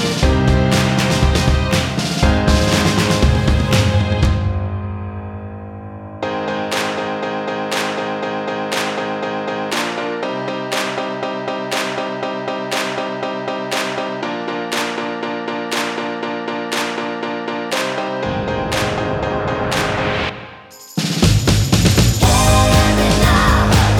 no Backing Vocals Dance 4:30 Buy £1.50